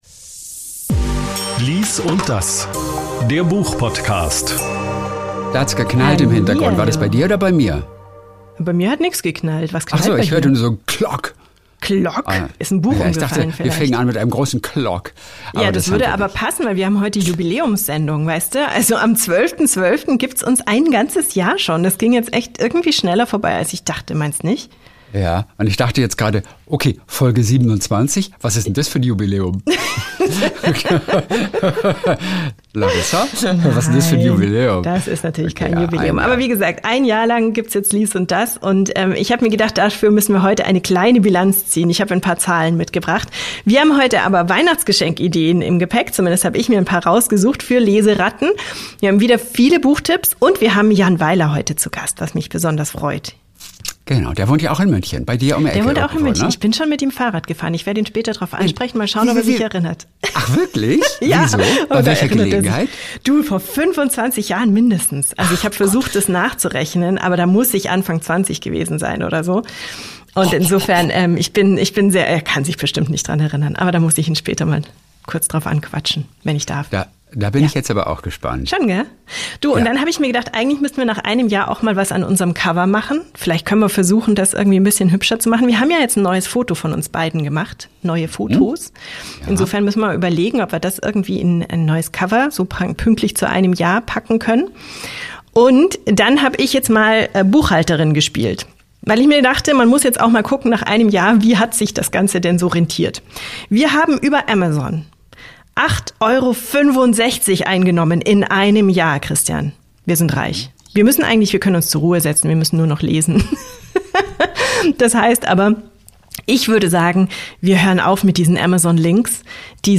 Heute ist Jan Weiler zu Gast mit seinem neuen Buch "Munk". Ansonsten stellen wir "Gun Love" vor und zwei Graphic Novels von Reinhard Kleist sowie eine Hawaii-Familiensaga.